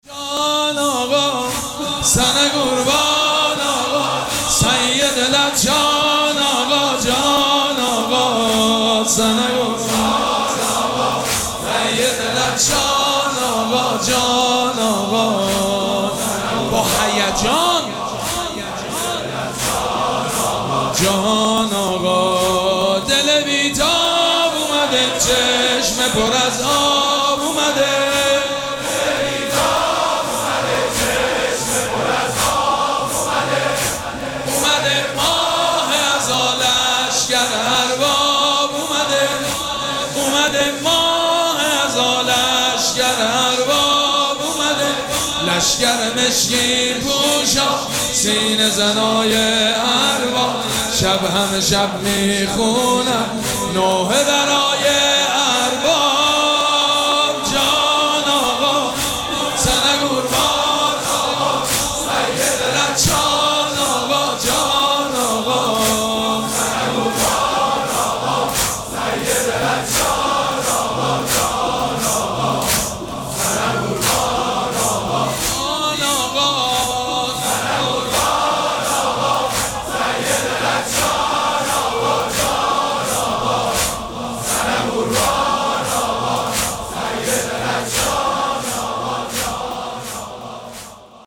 مراسم عزاداری شب نهم محرم الحرام ۱۴۴۷
مداح